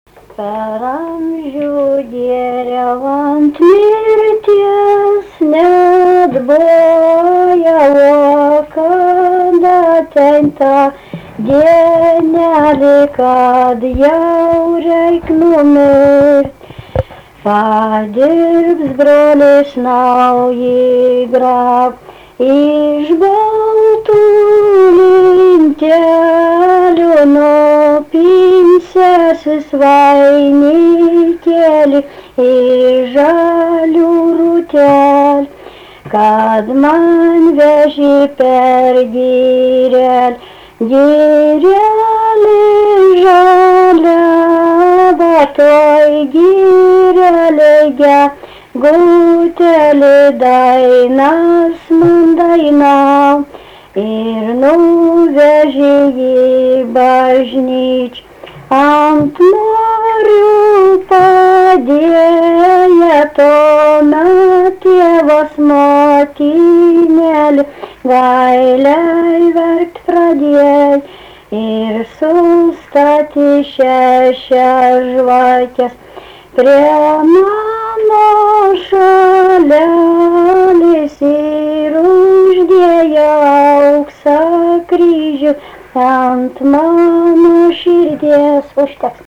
Tipas šokis Erdvinė aprėptis Kinčiuliai
Atlikimo pubūdis instrumentinis
Instrumentas smuikas
Instrumentinė muzika